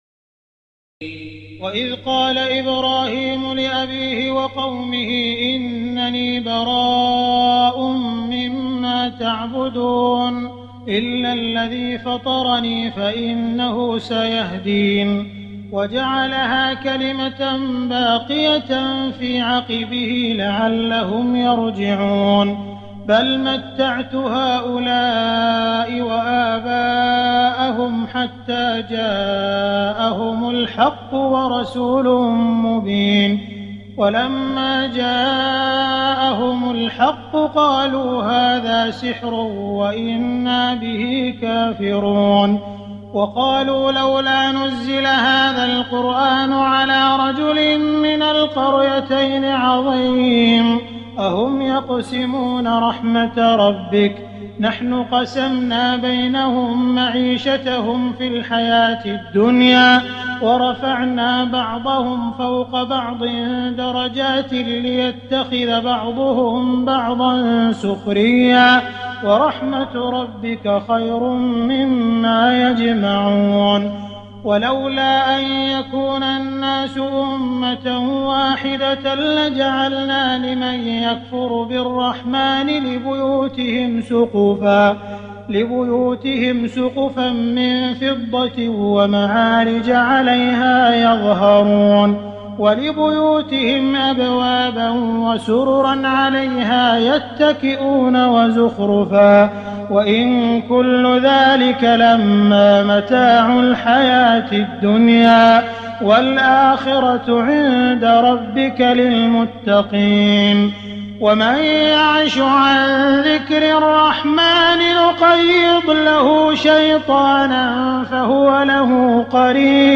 تراويح ليلة 24 رمضان 1419هـ من سور الزخرف (26-89) والدخان و الجاثية Taraweeh 24 st night Ramadan 1419H from Surah Az-Zukhruf and Ad-Dukhaan and Al-Jaathiya > تراويح الحرم المكي عام 1419 🕋 > التراويح - تلاوات الحرمين